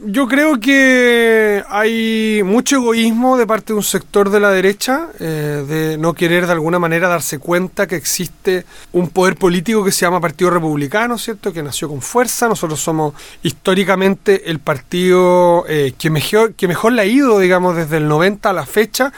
Lo anterior fue expuesto por el mismo candidato, en una entrevista realizada este jueves en el estudio de Radio Bío Bío.